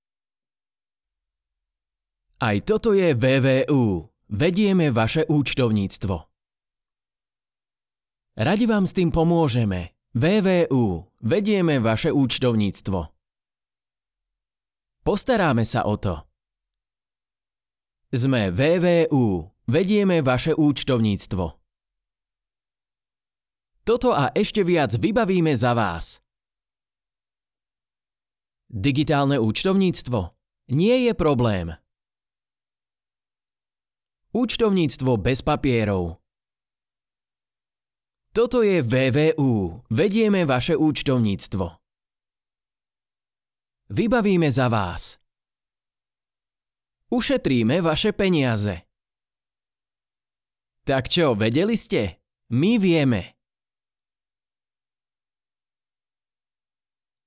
Profesinálny mužský VOICEOVER v slovenskom jazyku